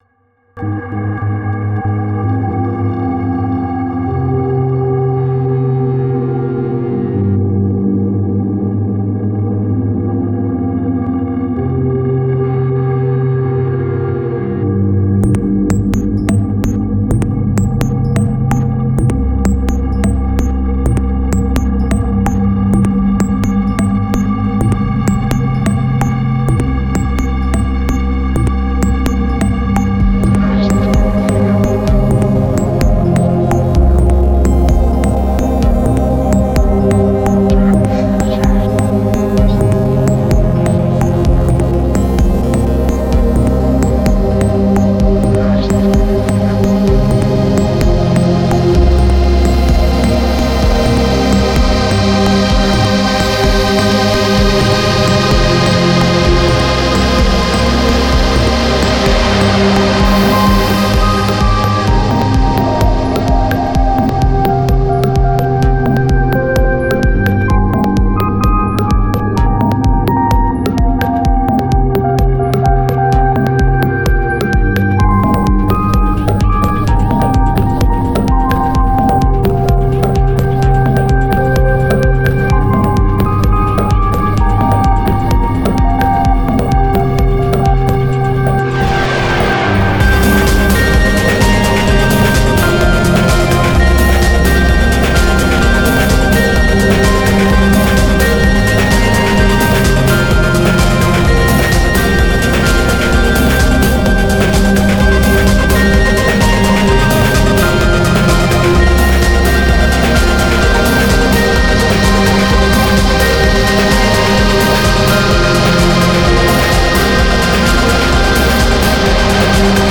electronic ambient